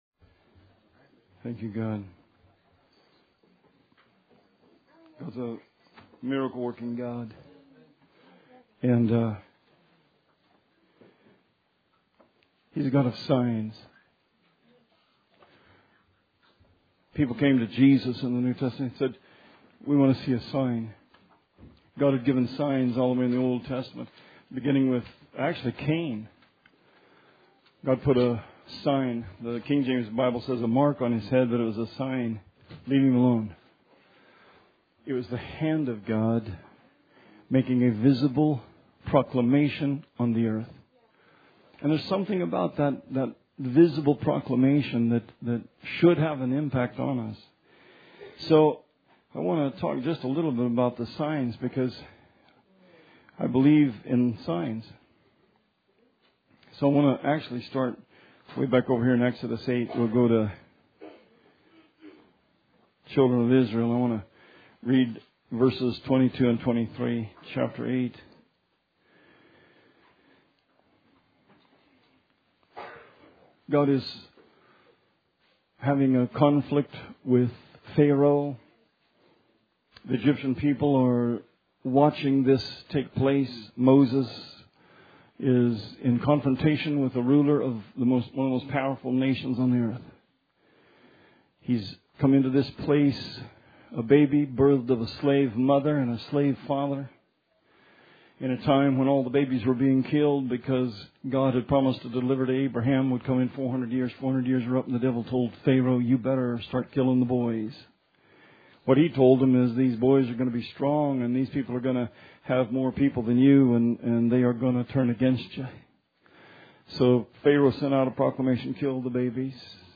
This sermon focuses on the flies in Egypt, binding the Word on your hand, sabbaths, Korah, Jonah, Ahaz & the sign of the sun dial for Hezekiah.